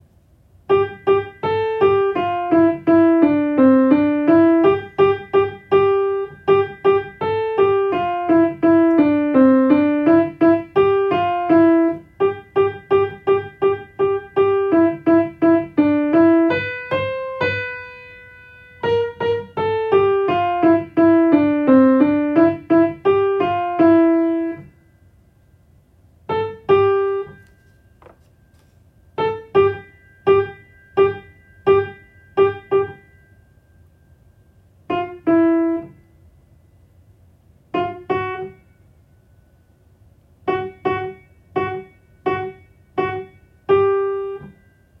音とり音源
アルト